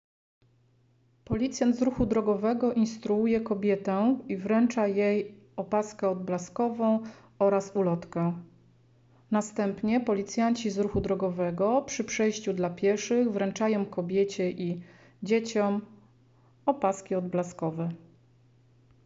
Opis nagrania: audiodeskrypcja